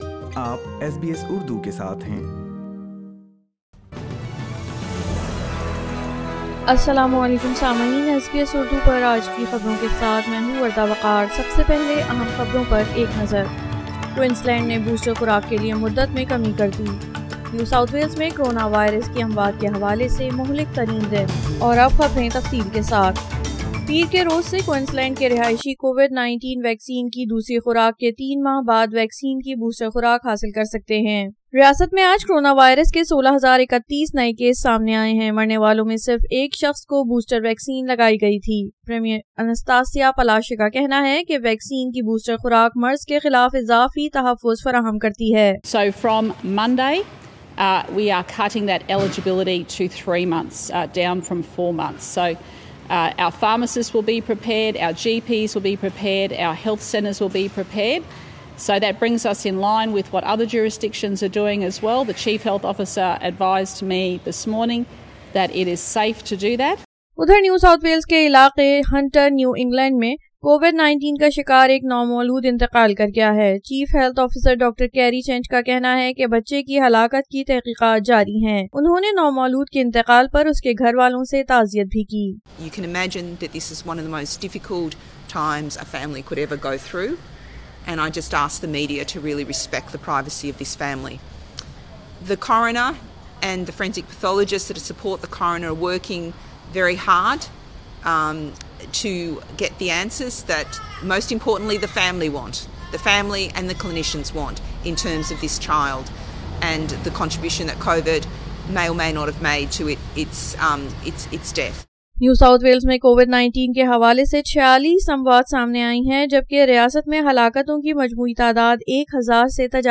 SBS Urdu News 21 January 2022